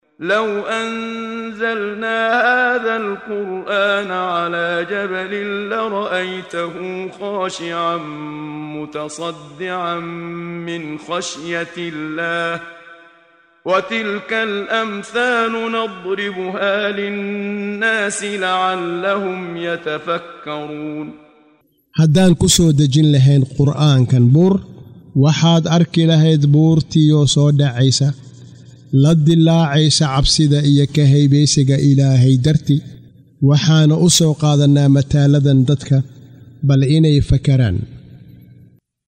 Waa Akhrin Codeed Af Soomaali ah ee Macaanida Suuradda Al-Xashar ( Kulminta ) oo u kala Qaybsan Aayado ahaan ayna la Socoto Akhrinta Qaariga Sheekh Muxammad Siddiiq Al-Manshaawi.